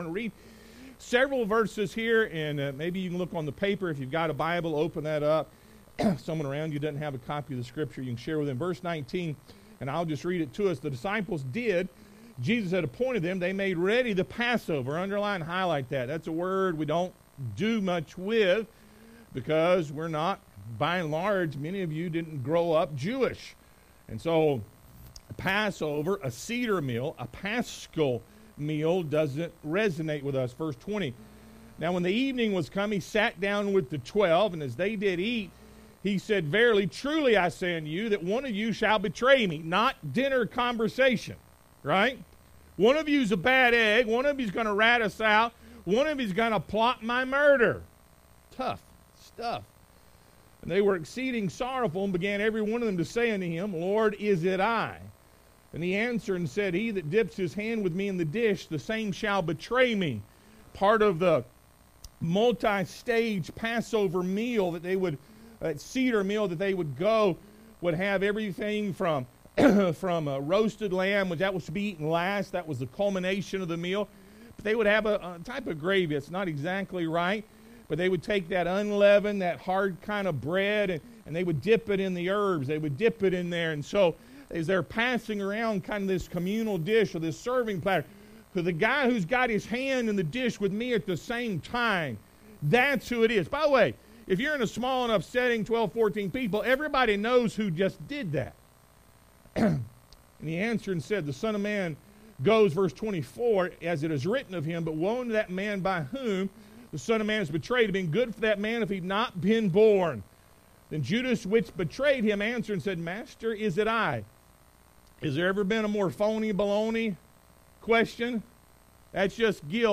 Matthew 26:27-30 Service Type: Sunday AM What does the Lord's Table have to do with Easter?